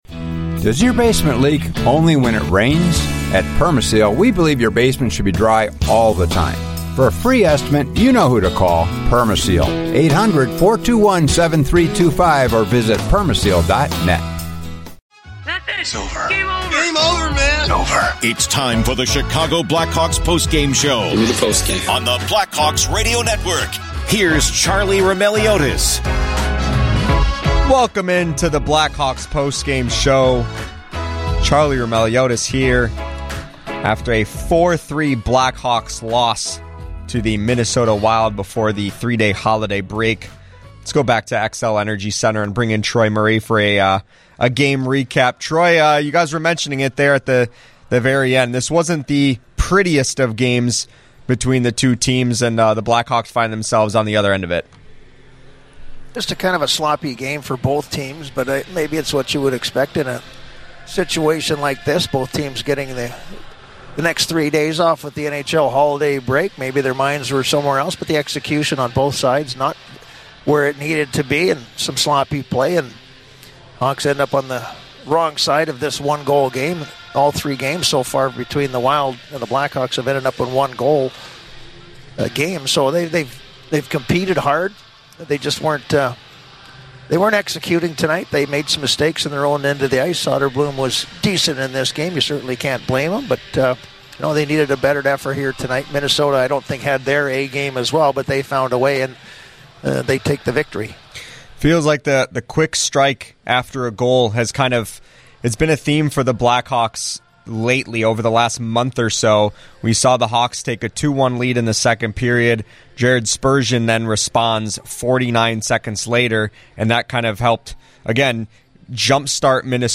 Troy Murray joins the discussion from Xcel Energy to talk about the Blackhawks’ comeback effort falling short in the 3rd period.
Later in the show, hear postgame audio from Jason Dickinson, Frank Nazar, and Anders Sorensen.